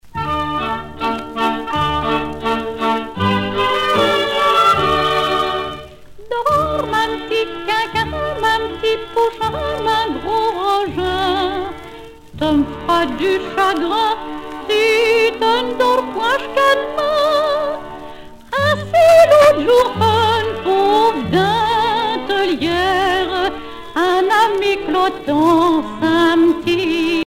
Berceuses diverses
Pièce musicale éditée